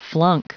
Prononciation du mot flunk en anglais (fichier audio)
Prononciation du mot : flunk